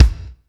Cherry Bomb Kick.wav